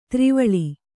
♪ tri vaḷi